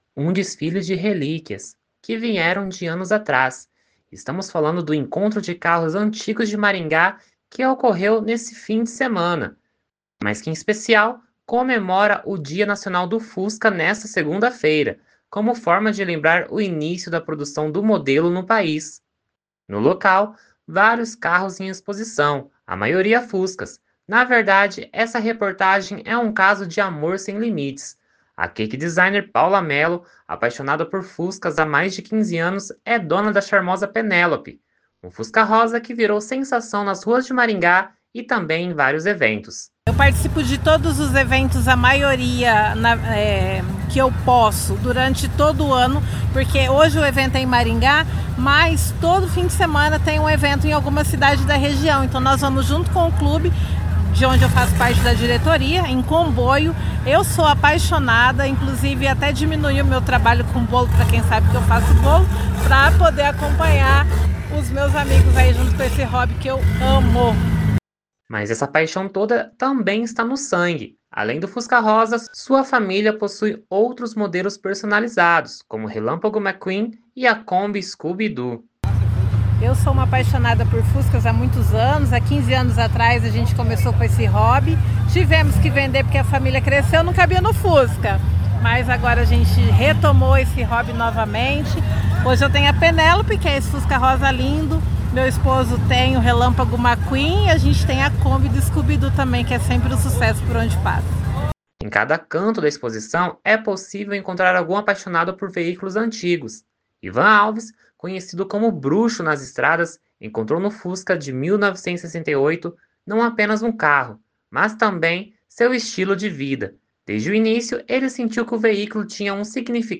Estamos no encontro de carros antigos de Maringá que em especial comemora o Dia Nacional do Fusca, nesta segunda-feira (20), como forma de lembrar o início da produção do modelo no pais.